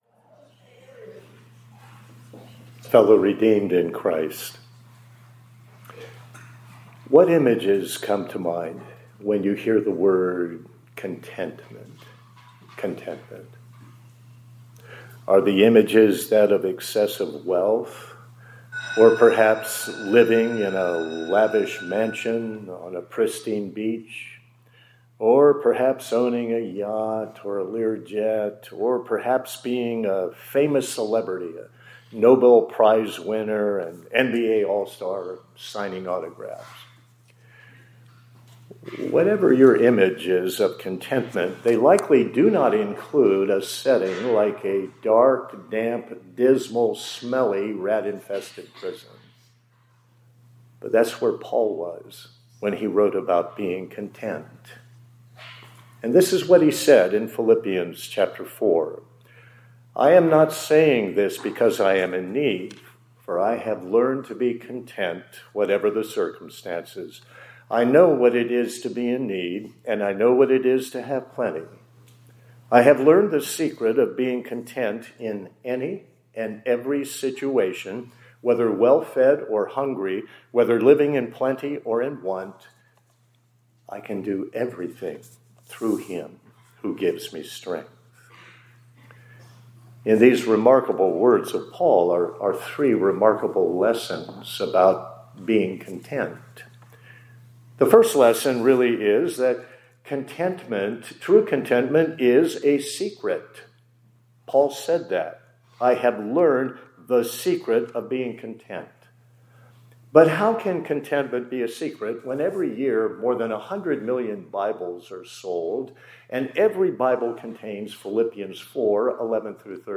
2026-01-21 ILC Chapel — The Secret of Contentment